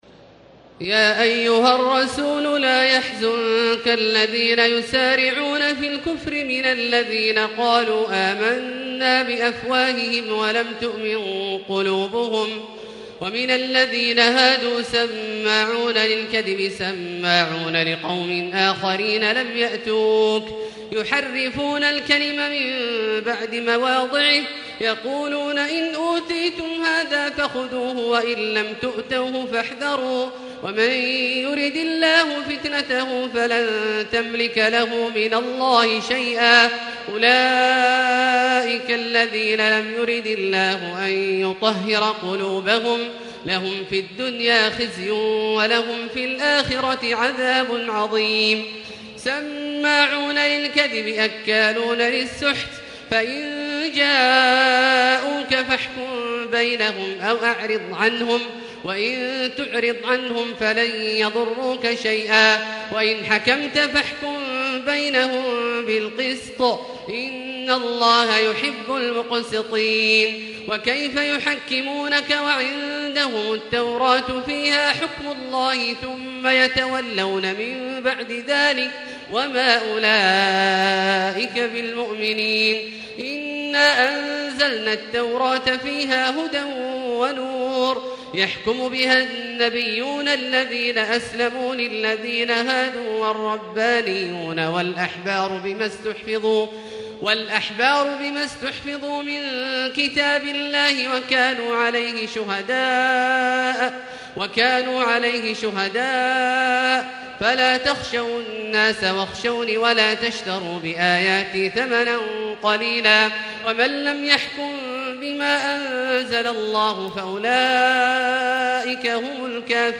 تراويح الليلة السادسة رمضان 1440هـ من سورة المائدة (41-120) Taraweeh 6 st night Ramadan 1440H from Surah AlMa'idah > تراويح الحرم المكي عام 1440 🕋 > التراويح - تلاوات الحرمين